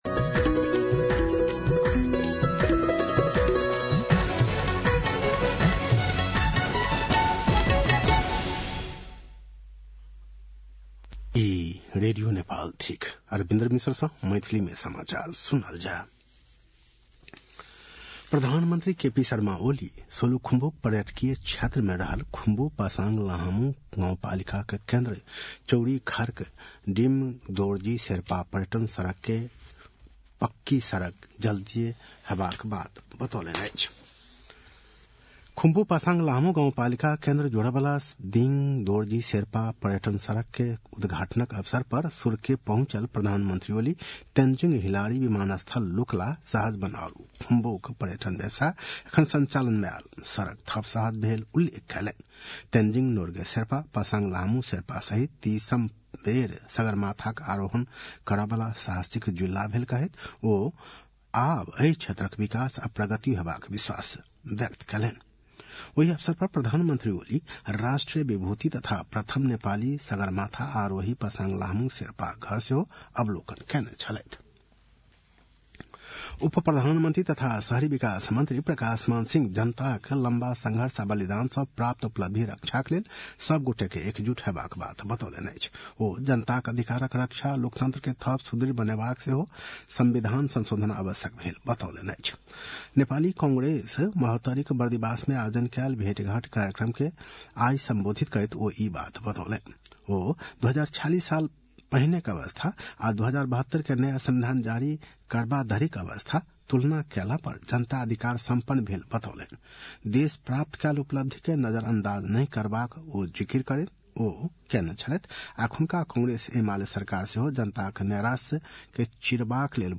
मैथिली भाषामा समाचार : २१ पुष , २०८१
Maithali-News-9-20.mp3